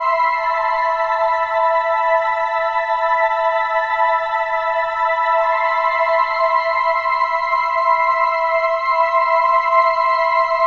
Synth 03.wav